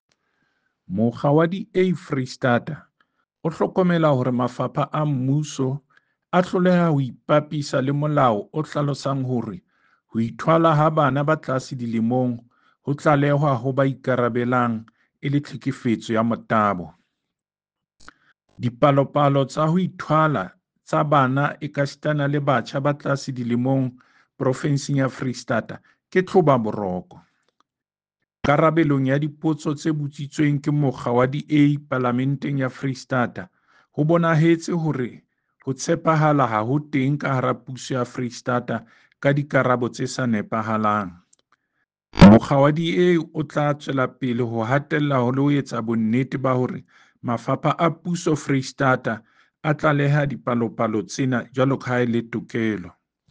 Sesotho soundbites by David Masoeu MPL and